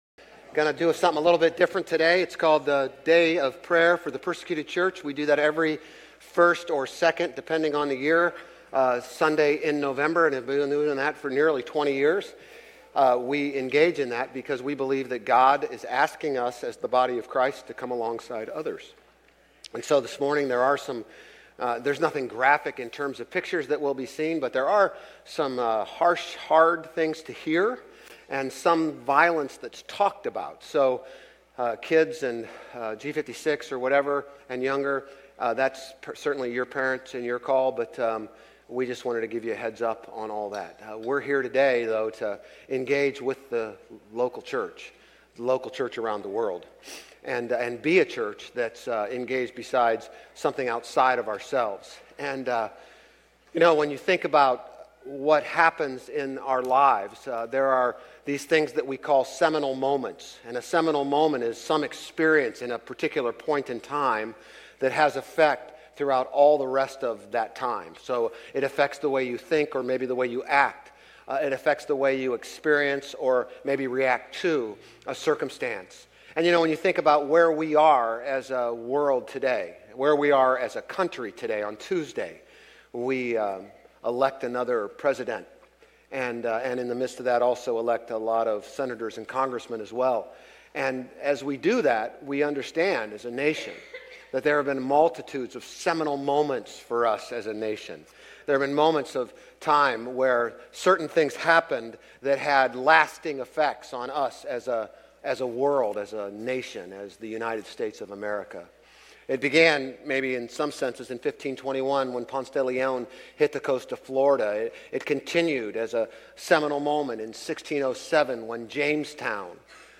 Grace Community Church Old Jacksonville Campus Sermons International Day of Prayer for the Persecuted Church Nov 03 2024 | 00:34:46 1x 00:00 / 00:34:46 Subscribe Share RSS Feed Share Link Embed